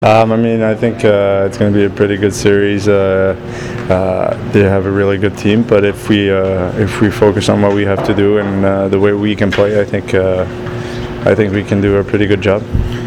I attended practice and talked with plenty of guys on the team.